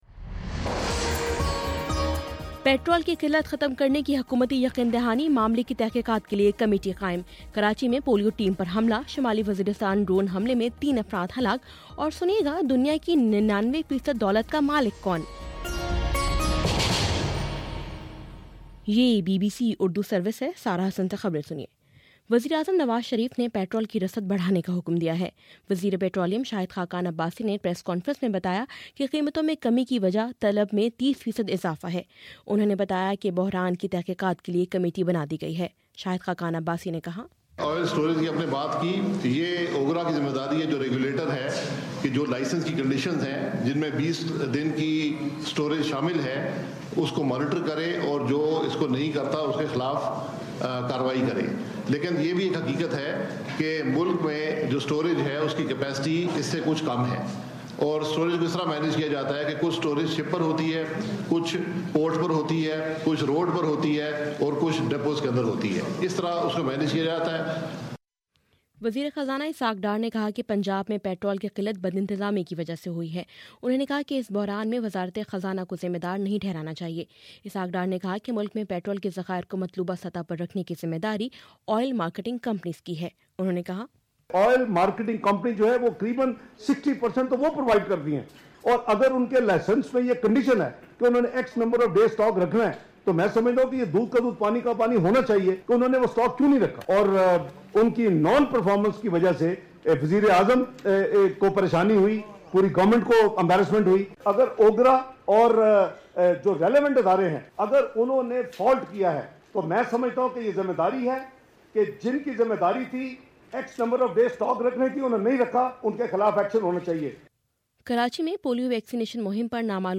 جنوری19: شام سات بجے کا نیوز بُلیٹن